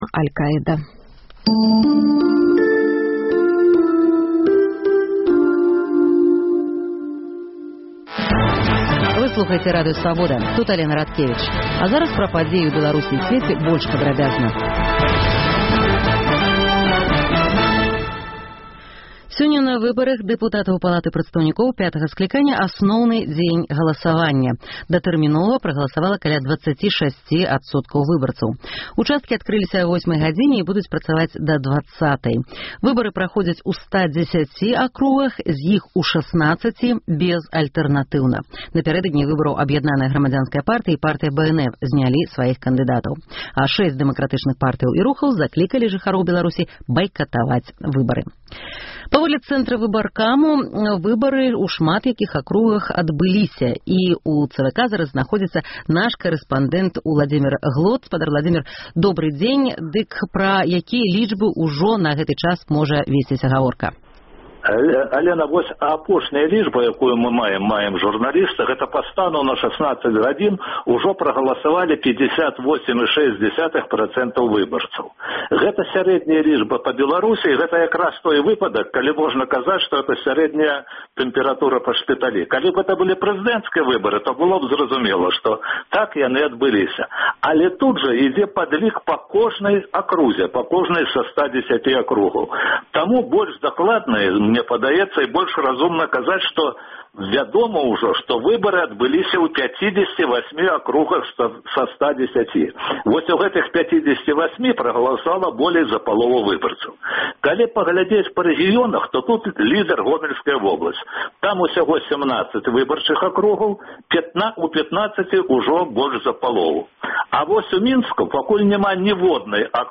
Навіны Беларусі і сьвету Паведамленьні нашых карэспандэнтаў, званкі слухачоў, апытаньні ў гарадах і мястэчках Беларусі